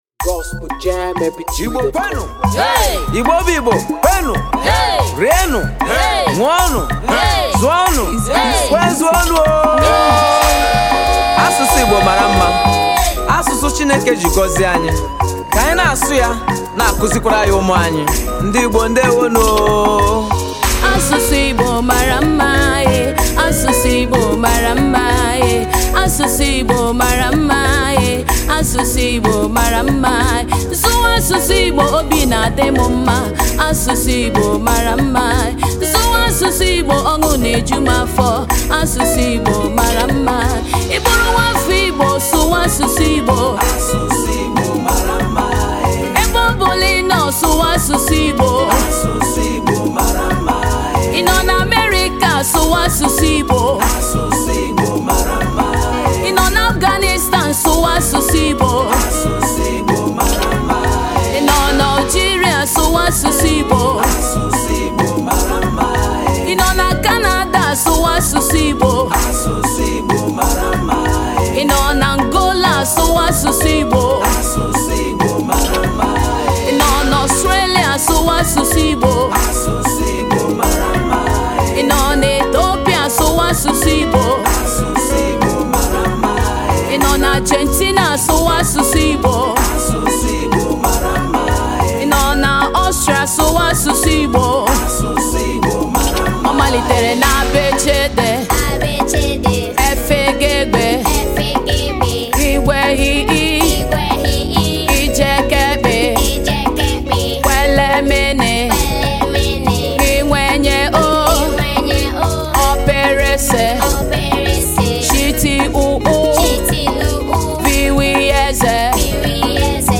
gospel
traditional igbo sound